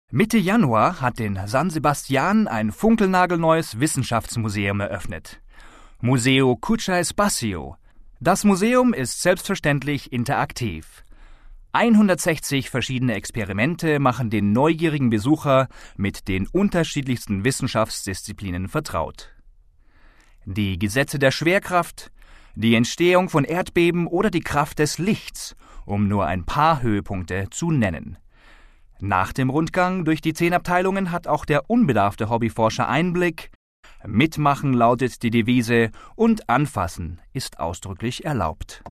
Region/Dialect German
Gender Male
Voice Sample